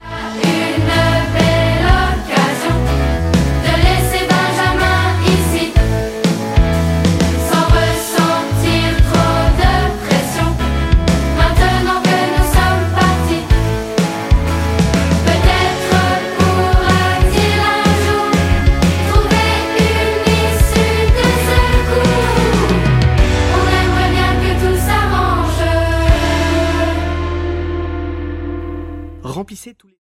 Album musical